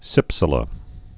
(sĭpsə-lə)